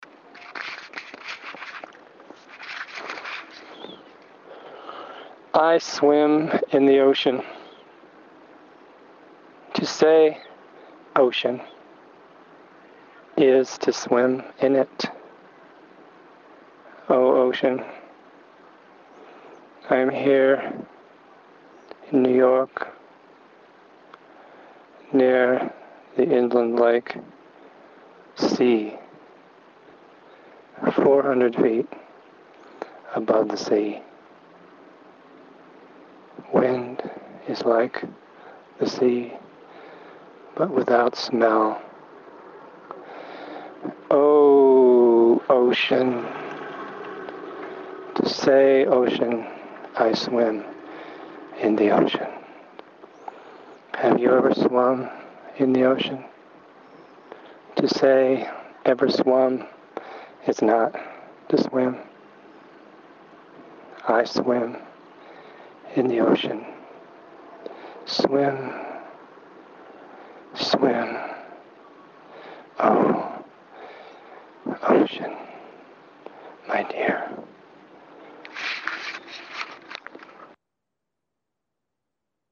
Spoken Poem.
i-swim-poem.mp3